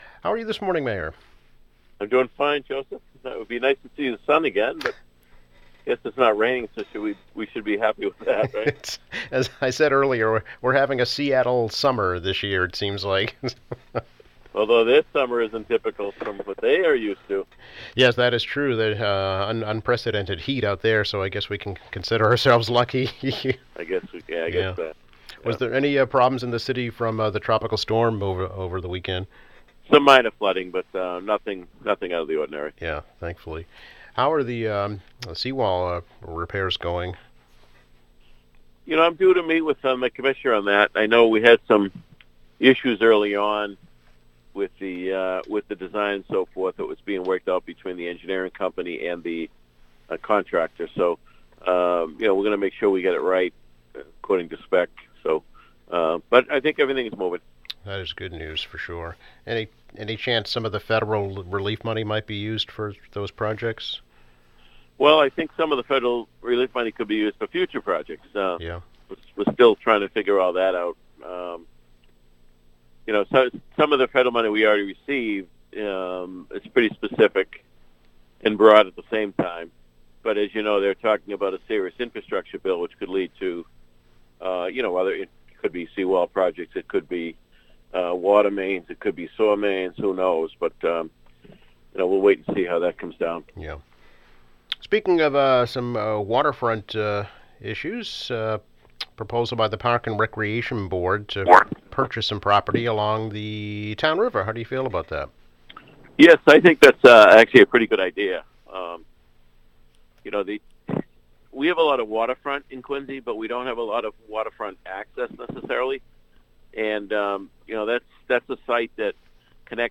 Quincy Mayor Thomas Koch speaks about his appointment of a new committee to explore issues of diversity, equity, and inclusion.